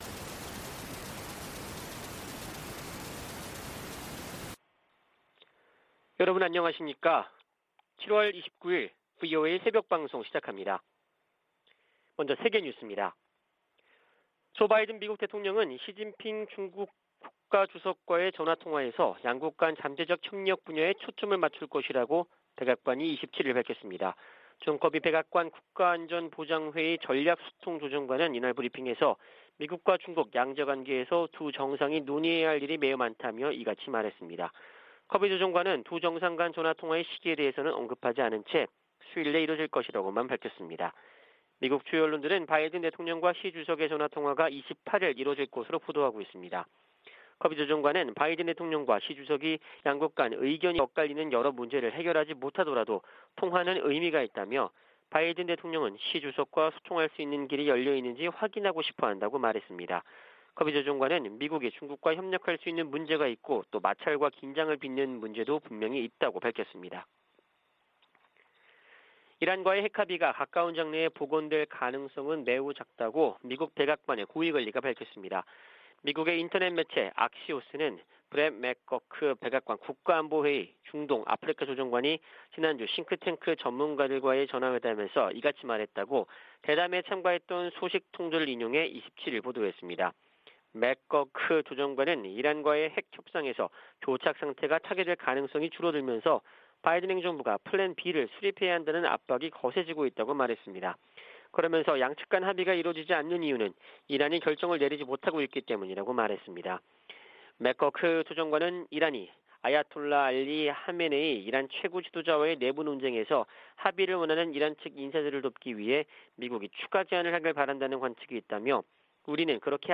VOA 한국어 '출발 뉴스 쇼', 2022년 7월 29일 방송입니다. 미국은 북한 정부 연계 해킹조직 관련 정보에 포상금을 두배로 올려 최대 1천만 달러를 지급하기로 했습니다. 북한의 핵 공격 가능성이 예전에는 이론적인 수준이었지만 이제는 현실이 됐다고 척 헤이글 전 미 국방장관이 평가했습니다. 김정은 북한 국무위원장이 미국과 한국을 강력 비난하고, 미국과의 군사적 충돌에 철저히 준비할 것을 다짐했다고 관영 매체들이 보도했습니다.